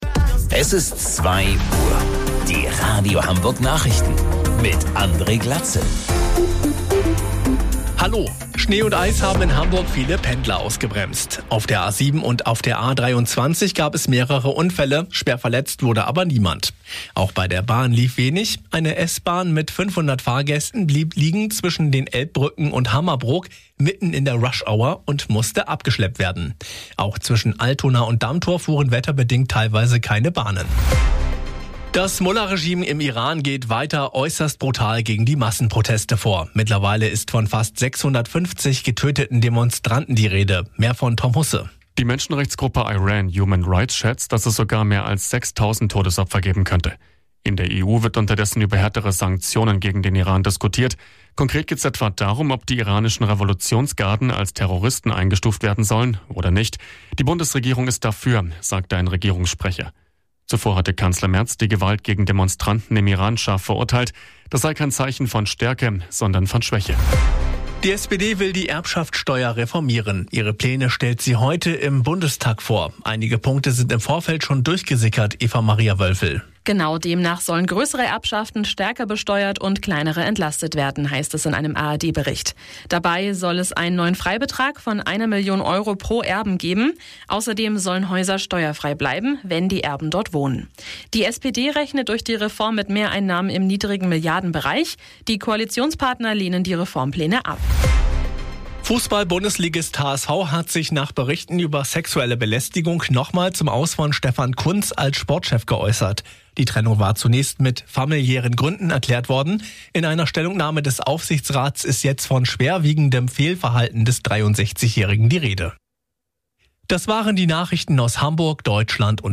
Radio Hamburg Nachrichten vom 13.01.2026 um 02 Uhr